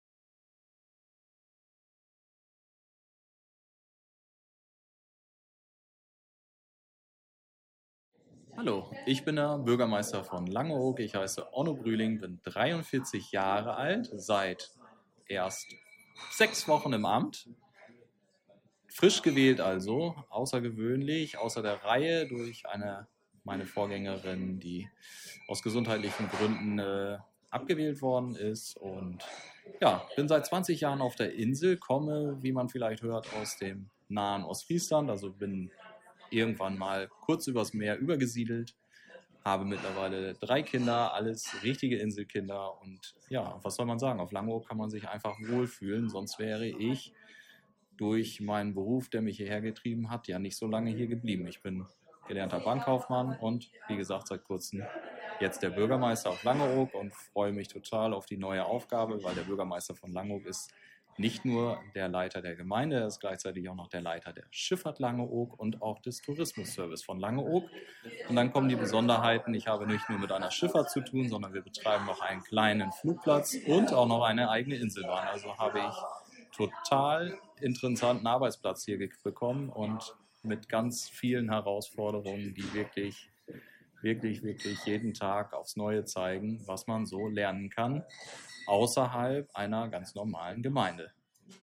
Beim Abendessen im Silt & Sand haben wir Gelegenheit uns mit dem frischgebackenen Bürgermeister von Langeoog, Onno Brüling, zu unterhalten.